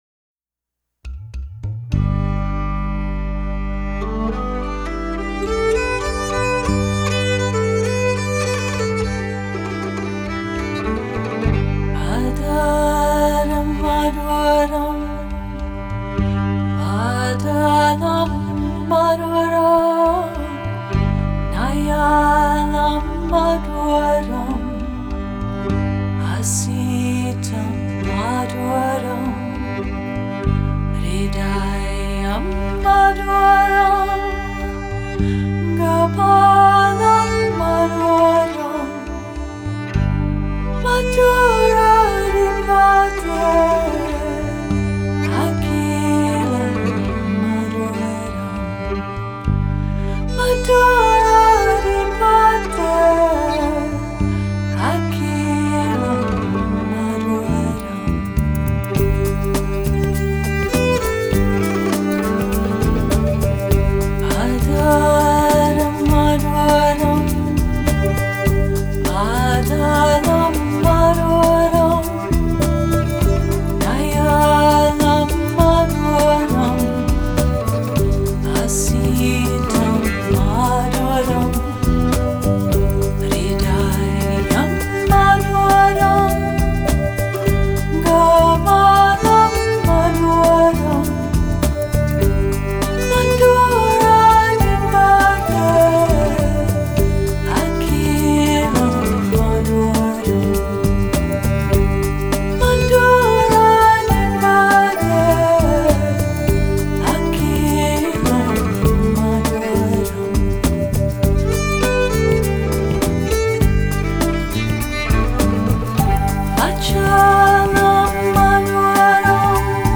американский нью-эйдж дуэт из Сан-Франциско
индуистскую религиозную музыку в современной обработке